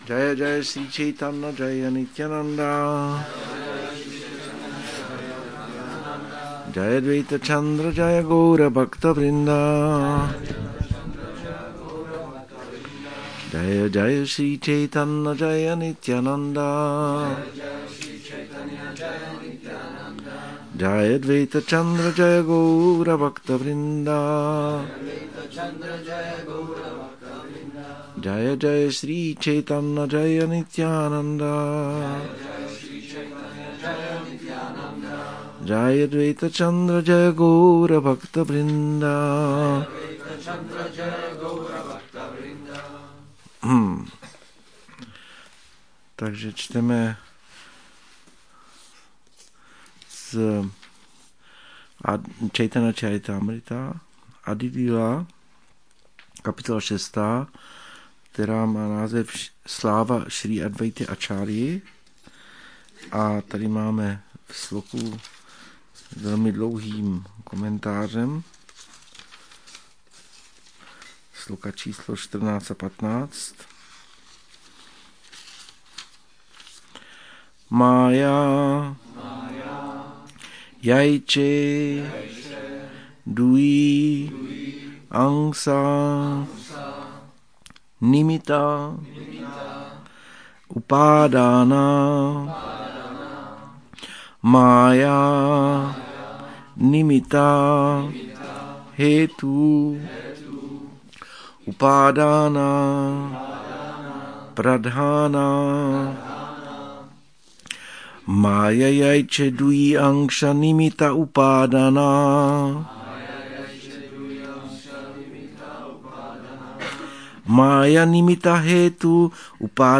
Přednáška CC-ADI-6.14-15